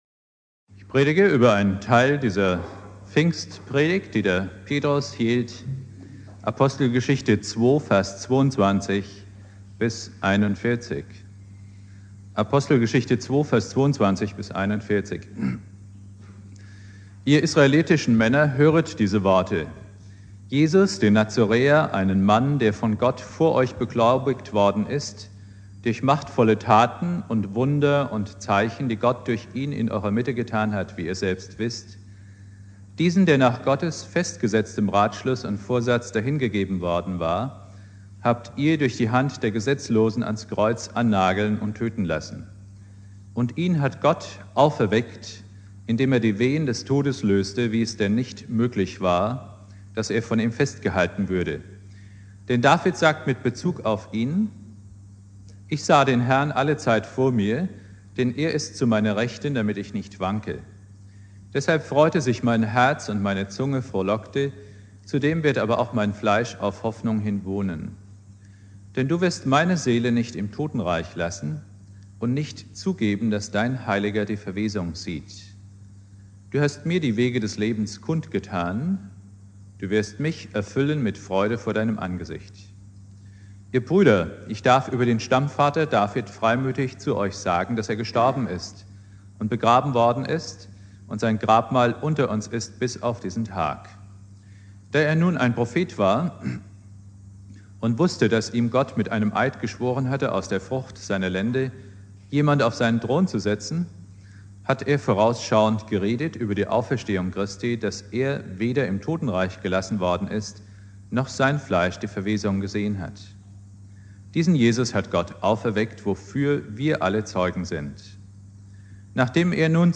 Predigt
Pfingstmontag Prediger